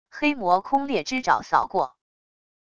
黑魔空裂之爪扫过wav音频